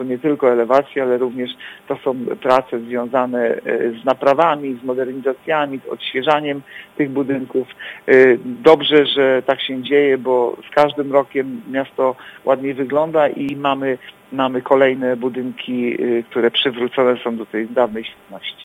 Mówi Artur Urbański zastępca prezydenta Ełku.